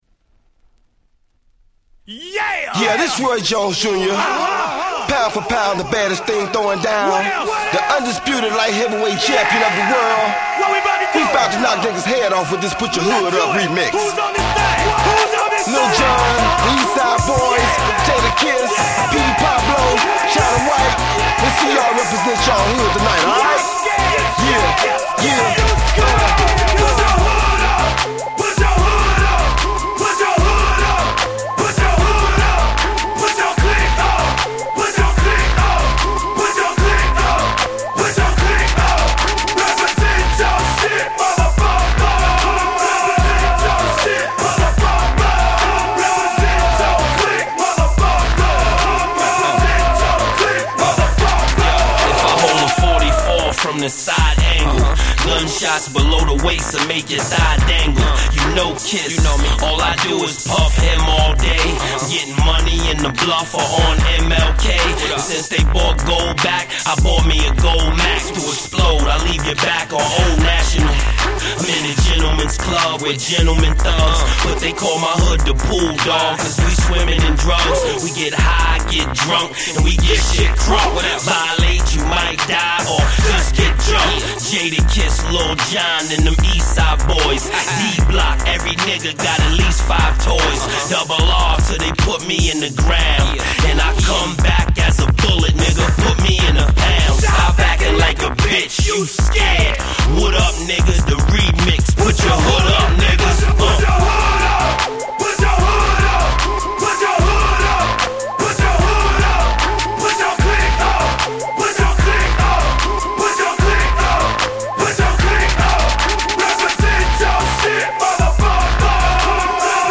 Dirty South Classic !!